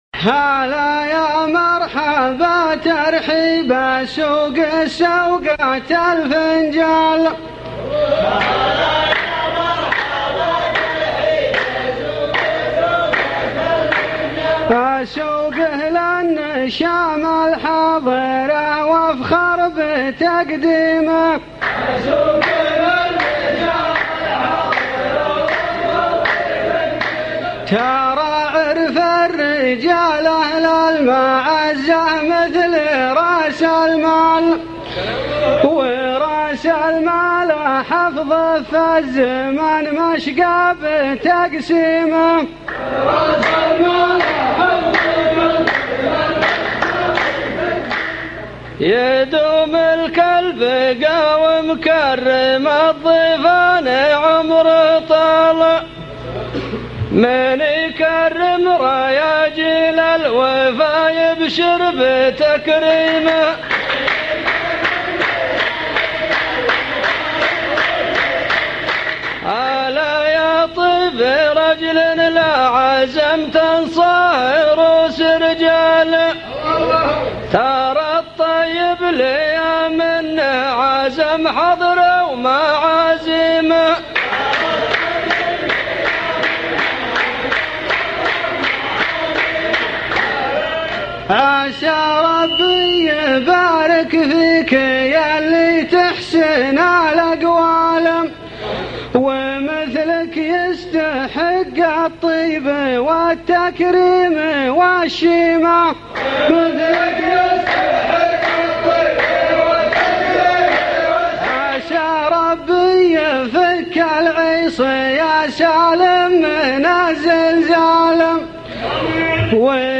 محاوره صوتية